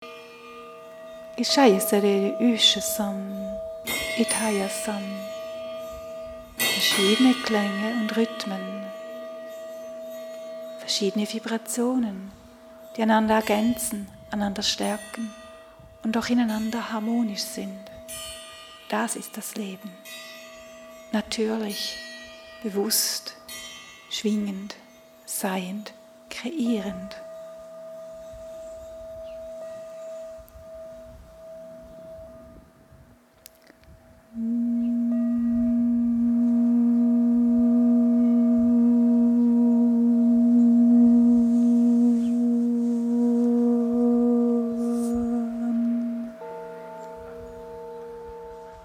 Momentum-Aufnahmen